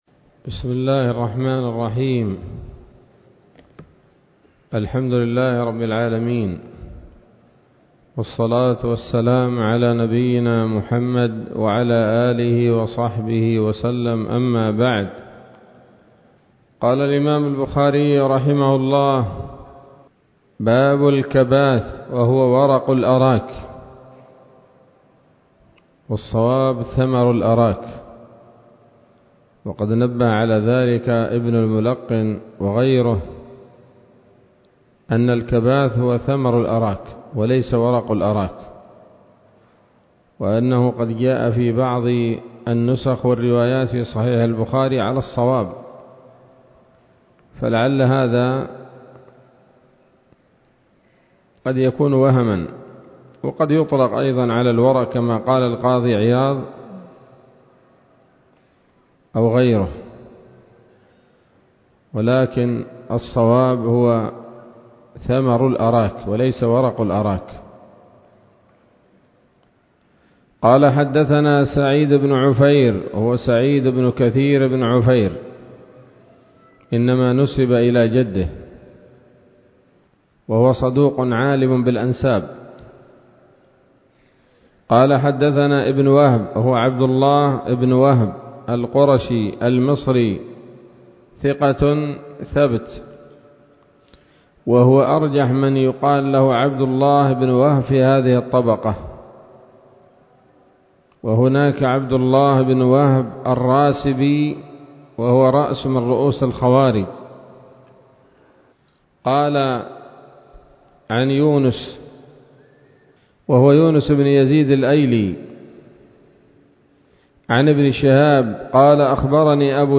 الدرس السابع والعشرون من كتاب الأطعمة من صحيح الإمام البخاري